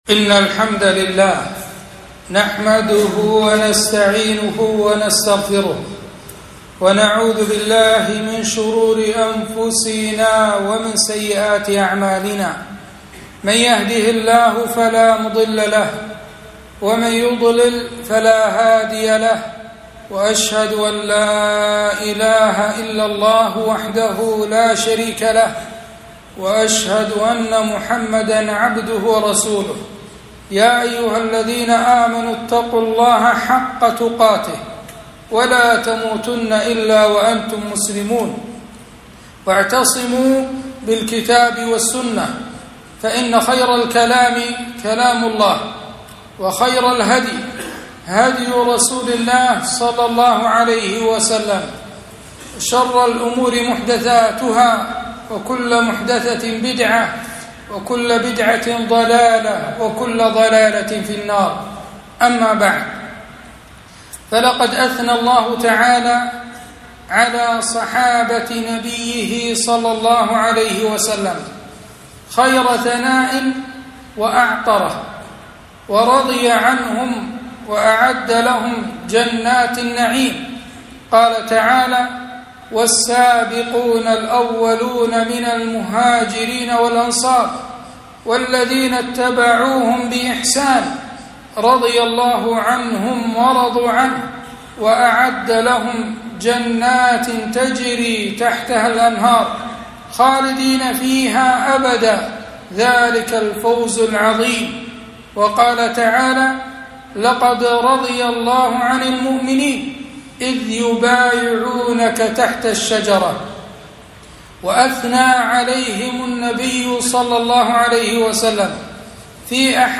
خطبة - لا تسبوا أصحابي